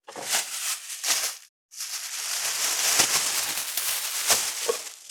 640コンビニ袋,ゴミ袋,スーパーの袋,袋,買い出しの音,ゴミ出しの音,袋を運ぶ音,
効果音